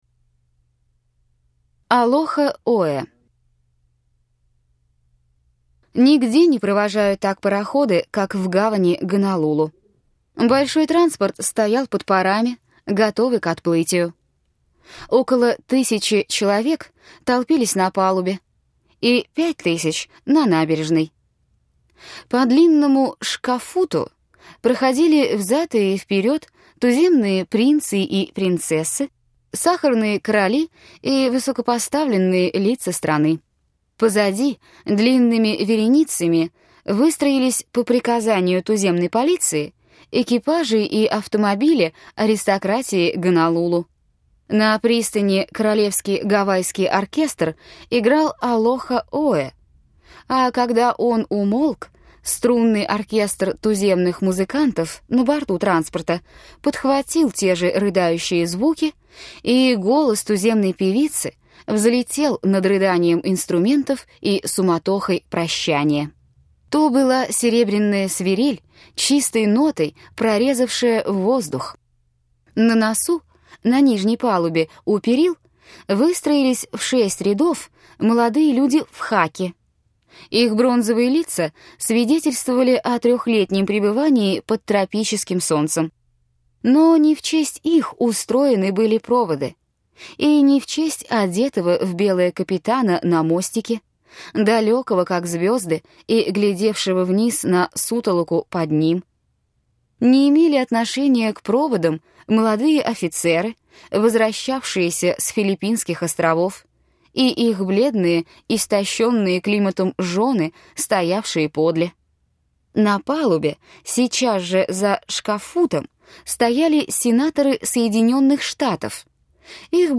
Аудиокнига Храм гордыни. Принцесса. Вечные формы и другие рассказы | Библиотека аудиокниг